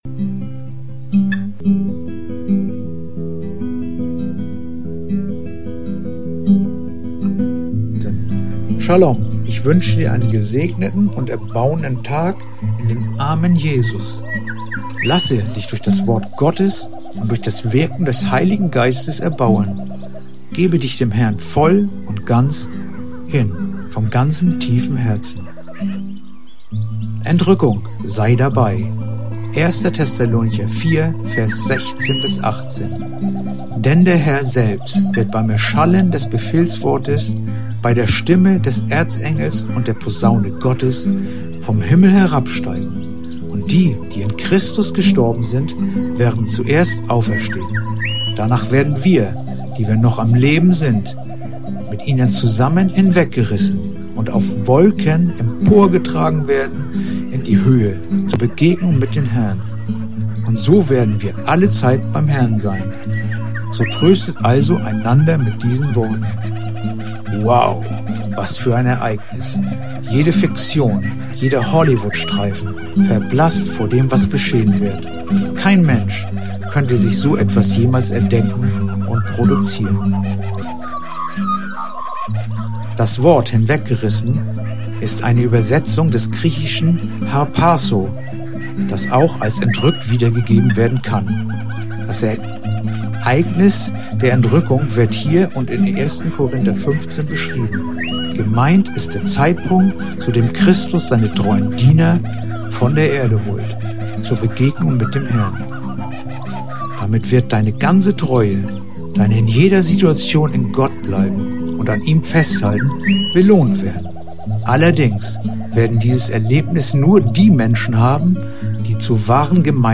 heutige akustische Andacht